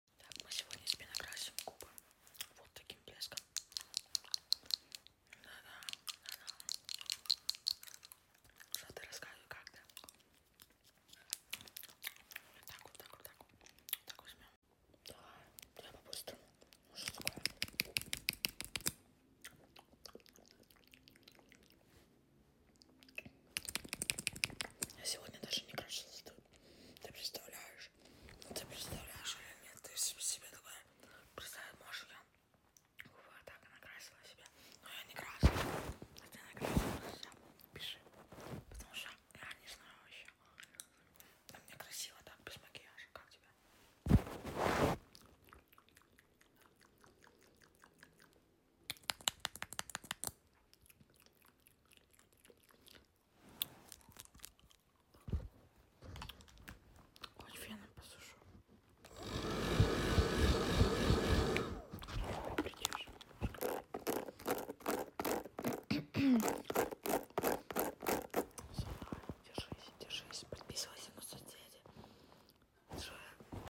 Асмр крашу тебе губы